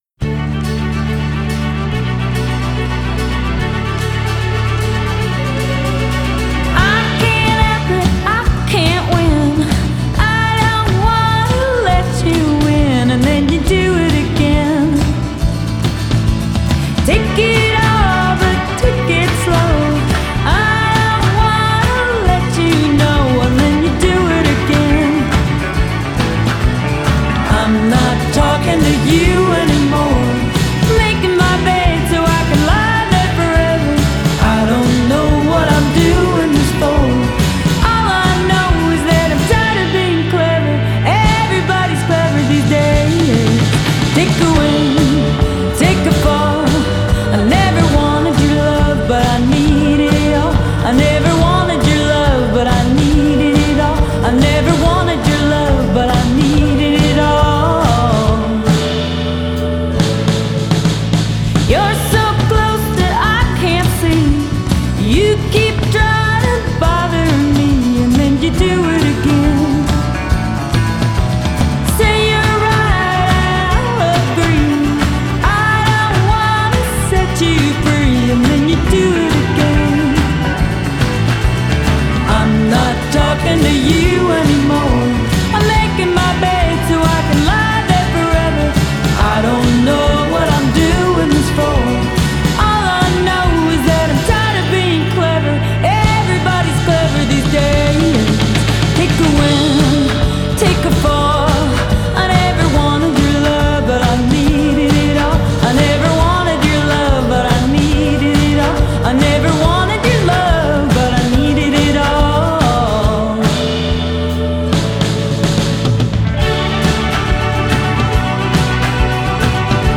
Genre: Indie Pop, Twee Pop